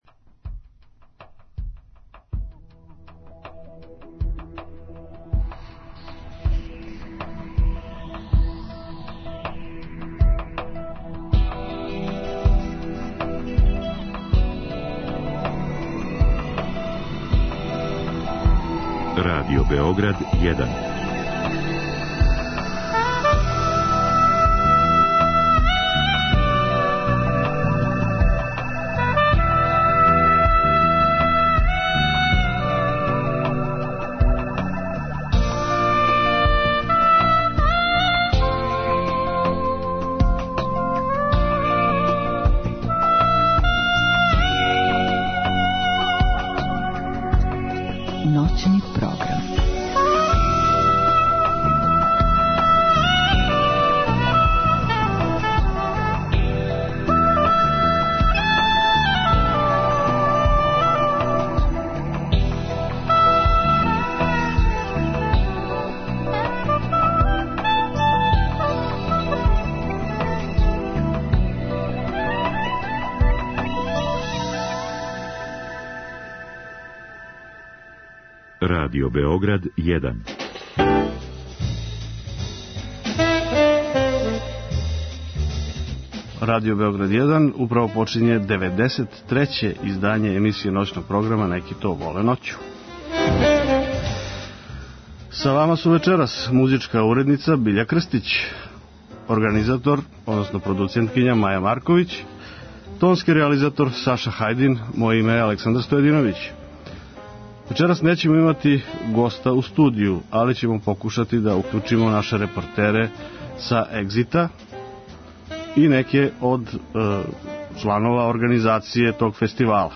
У 93. емисији „Неки то воле ноћу“ бавићемо се музичким фестивалом „Егзит“ који се од 7.-10. јула одржава у Новом Саду. Уз помоћ наших репортера и представника организатора који ће се укључивати у програм, покушаћемо да вам дочарамо атмосферу на Петроварадинској тврђави...